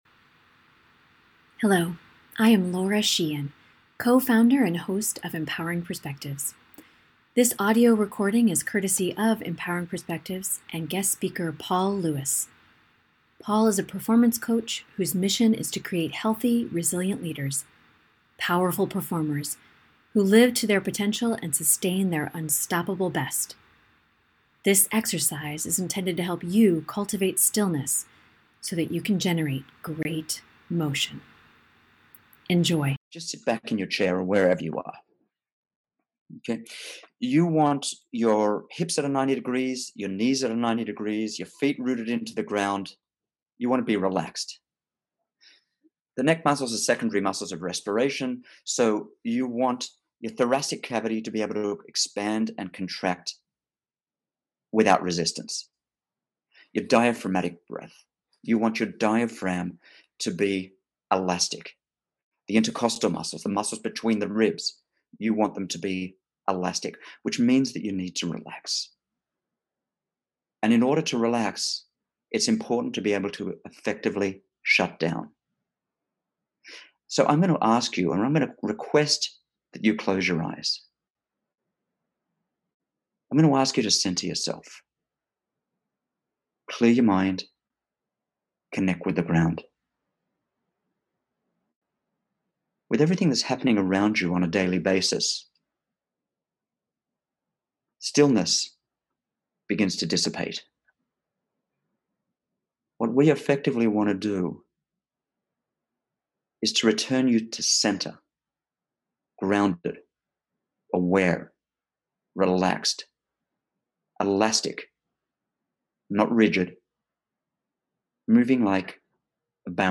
Cultivating Stillness Exercise